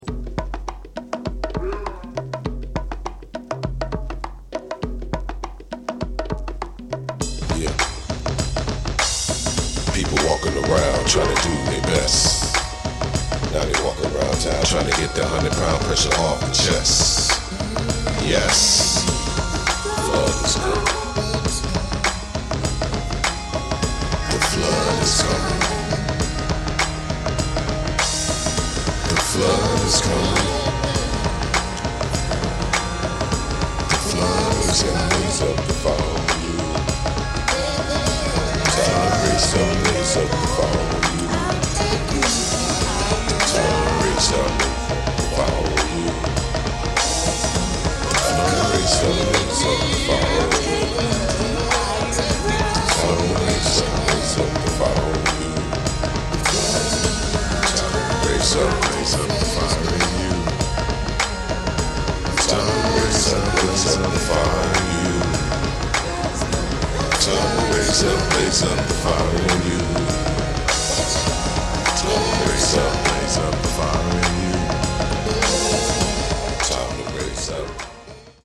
through a series of studio sessions